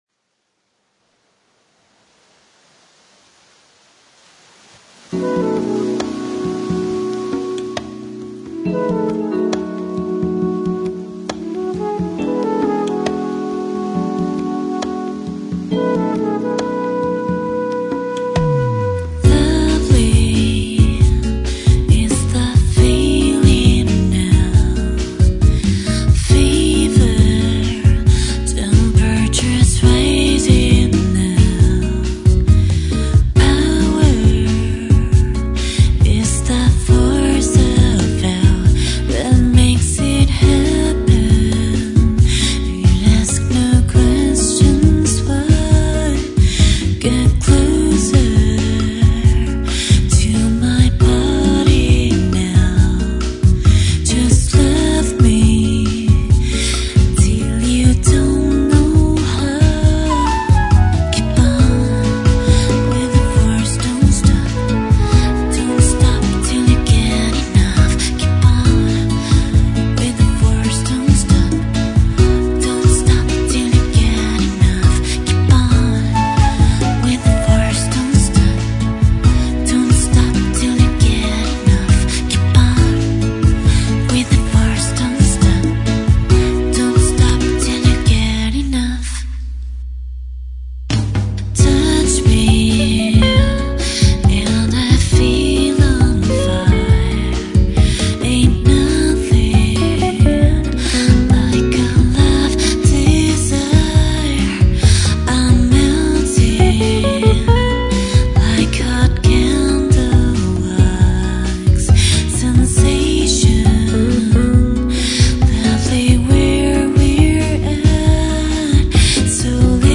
Bossa